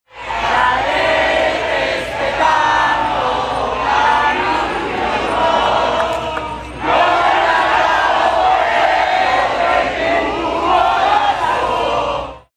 Maria Corina Machado, lidera opoziției din Venezuela, a reapărut în public la Oslo pentru prima dată în aproape un an, după ce a lipsit de la ceremonia de înmânare a Premiului Nobel pentru Pace. Machado a ajuns cu o zi întârziere la un hotel din Oslo, unde a salutat zeci de susținători și a cântat imnul Venezuelei de la balcon.
11dec-15-Ambianta-Machado-la-Oslo.mp3